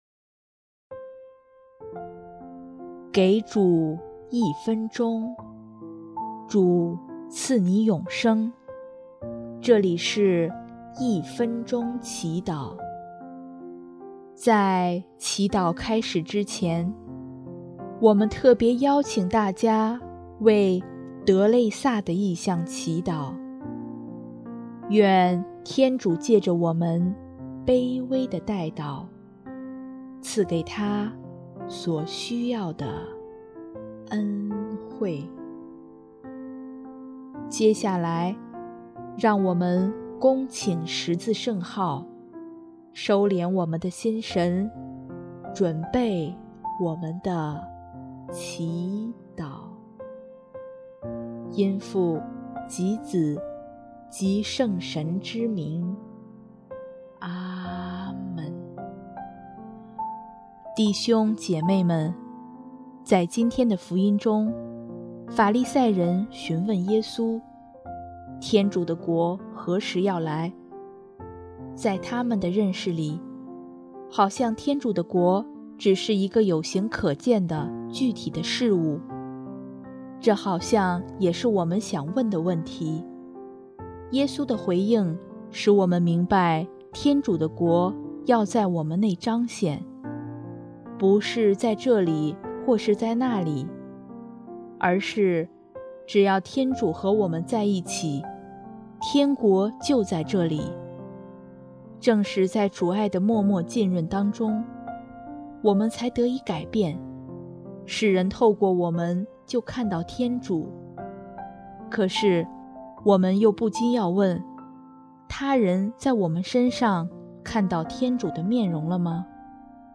音乐： 第一届华语圣歌大赛参赛歌曲《永恒的希望》